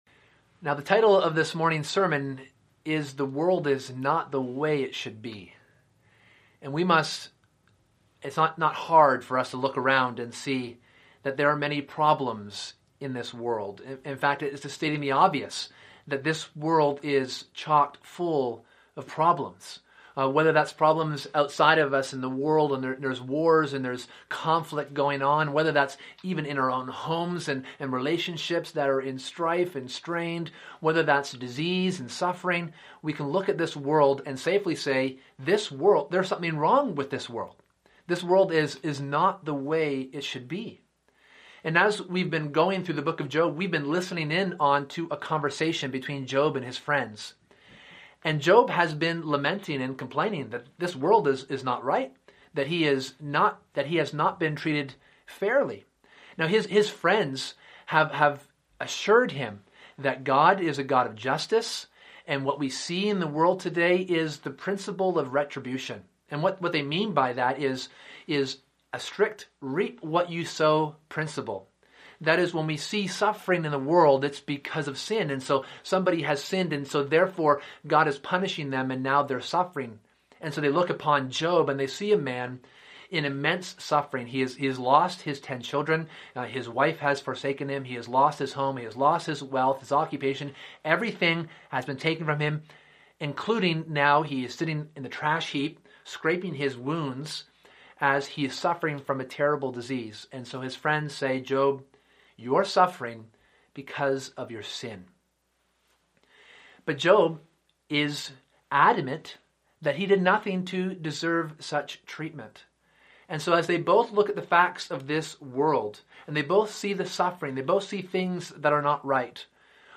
Sermon: The World Is Not the Way It Should Be
April 5, 2020 ( Sunday AM ) Bible Text